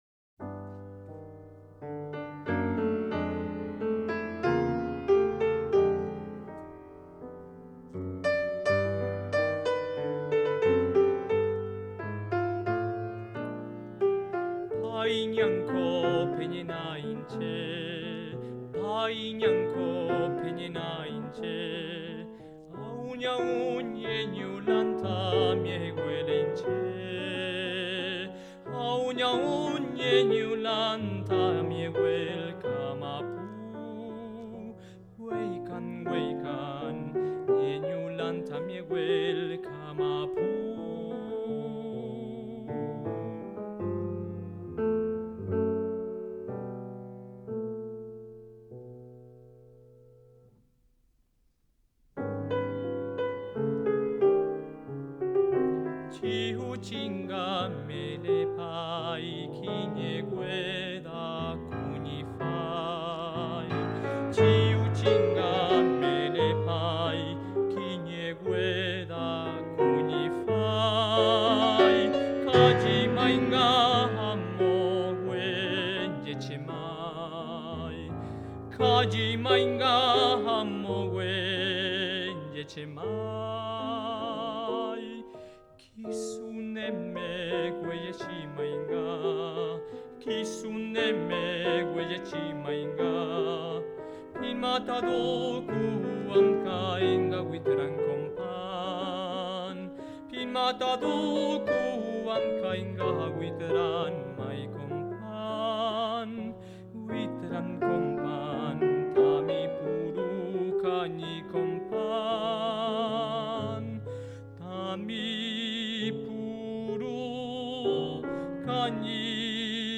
Canto
Música tradicional